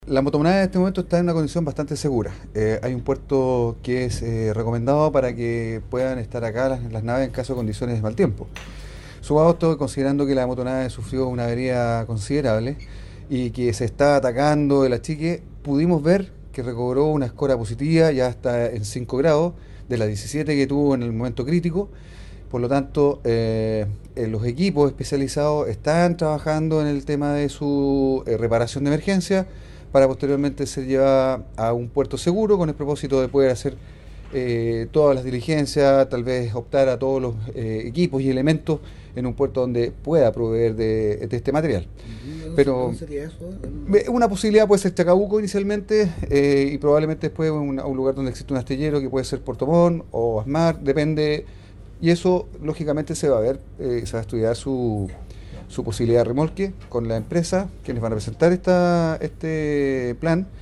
También las autoridades llegaron hasta la nave siniestrada, señalando el gobernador marítimo de Aysén, Horacio San Martín, que ahora que se encuentra en un puerto seguro, se seguirá trabajando en mantener la flotabilidad y sacar el agua que se encuentra en el fondo de la nave.
10-GOBERNADOR-MARITIMO-AYSEN.mp3